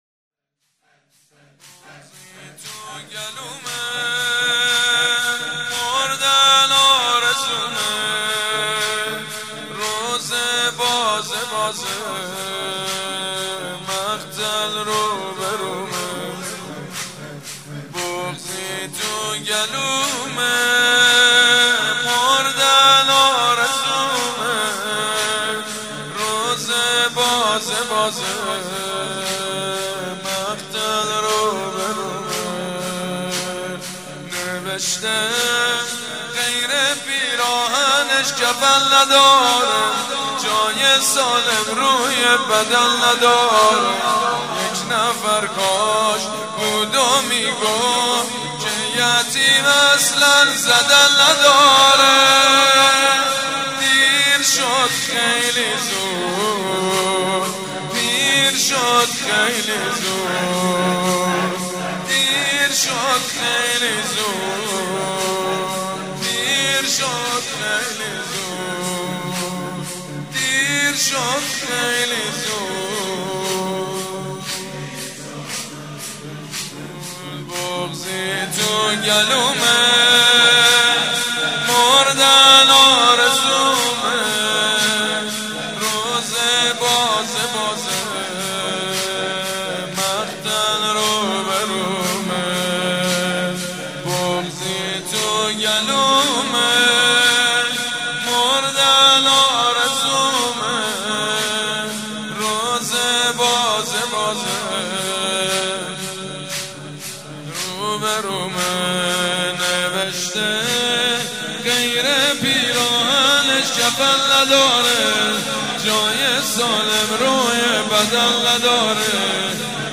بشنوید/ مداحی «مجید بنی‌فاطمه» در شب قدر